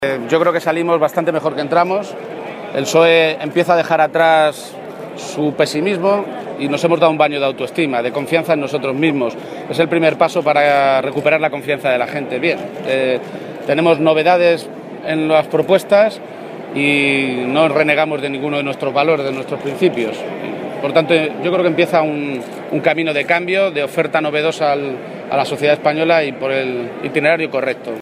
García-Page se pronunciaba de esta manera a preguntas de los medios de comunicación, a su llegada al Plenario de la Conferencia, esta mañana.